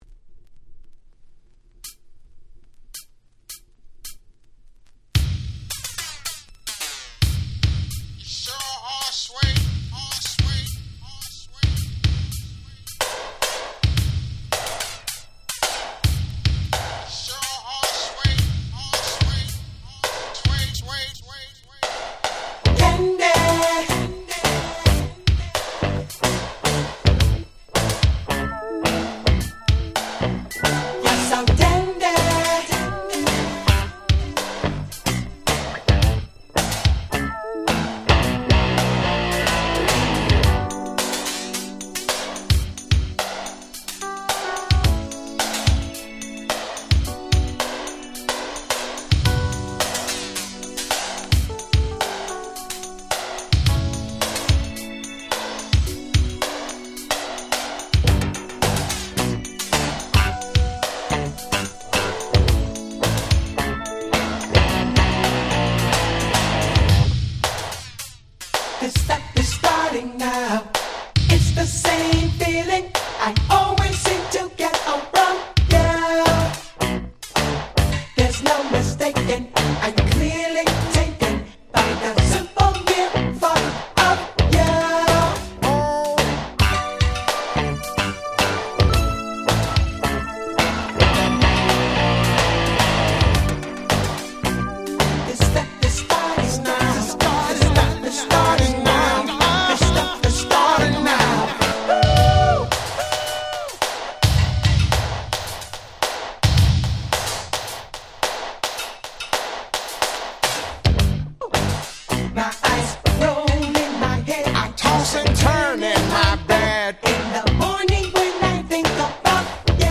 86’ Smash Hit Disco/Funk !!